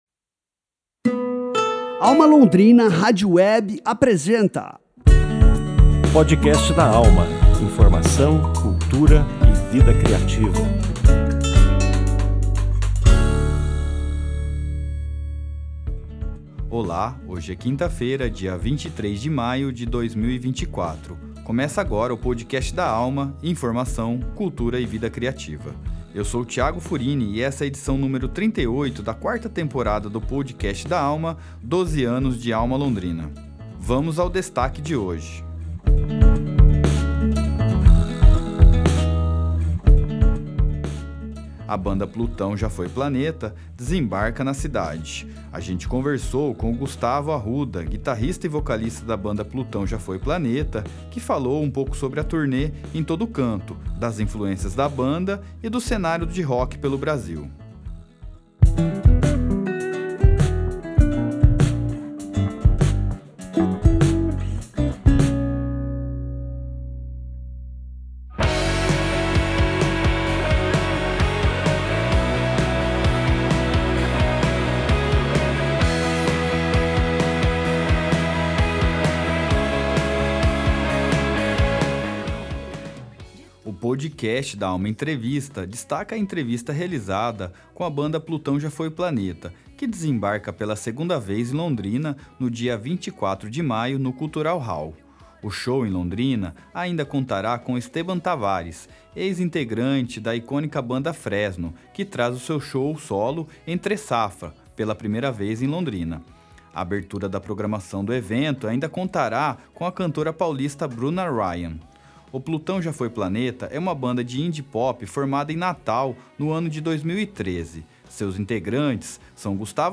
Podcast da AlmA Entrevista